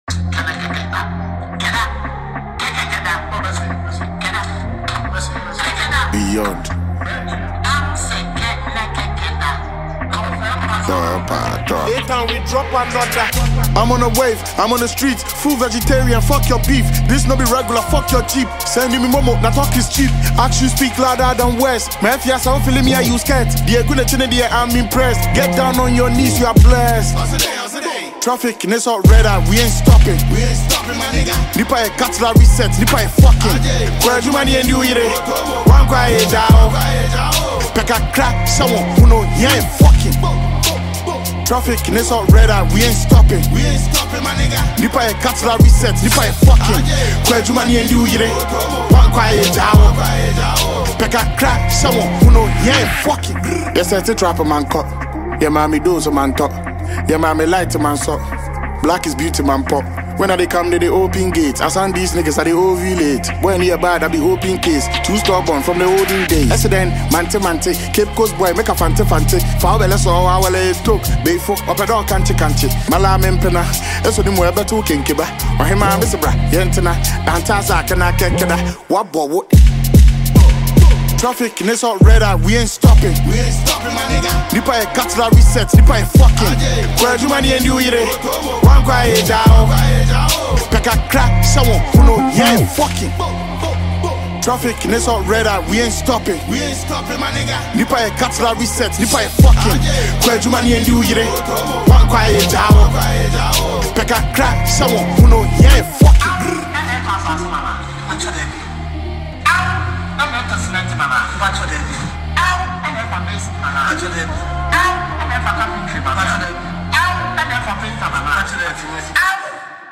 Heavyweight Ghanaian rapper